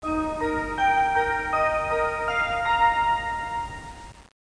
220410 岐阜駅
JR東海 在来線接近メロディ
jrtokai-melo.mp3